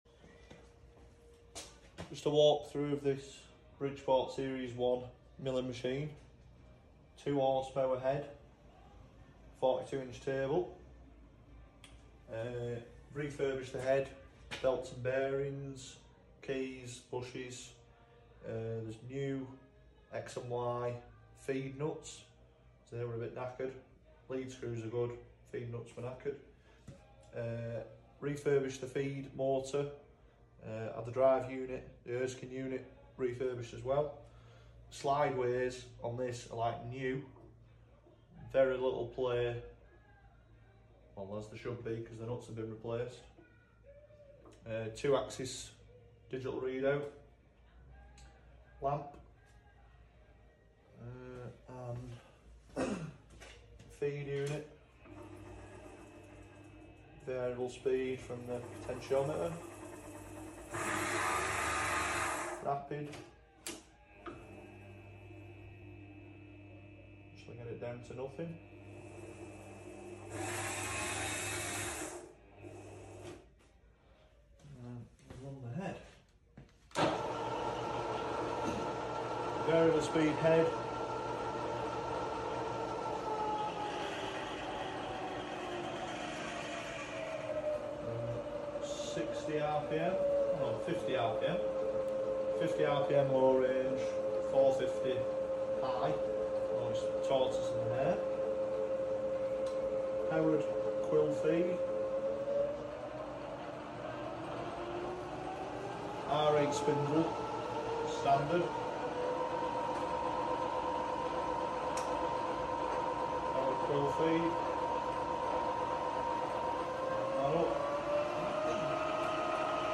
Bridgeport Milling Machine Demo 🦾 sound effects free download